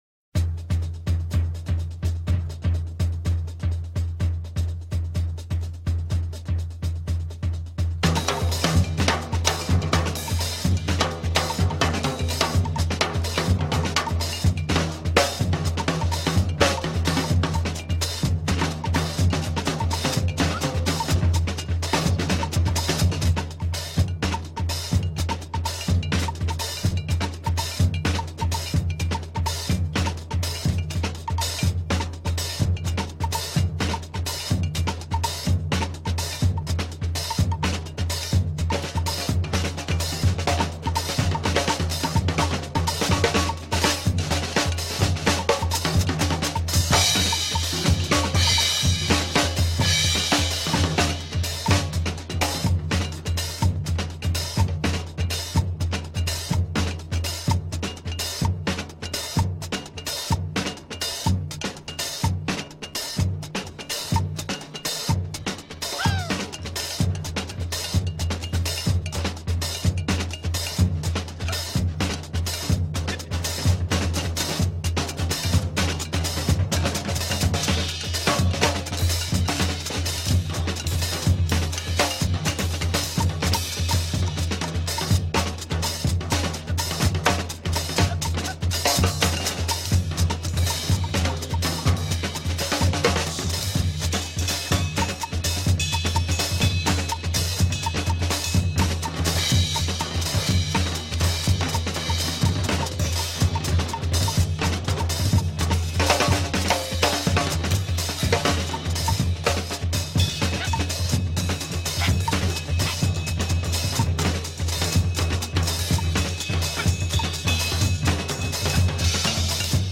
アフリカン・ルーツに根ざしたリズミカルなスピリチュアル・サウンドが繰り広げられた１枚で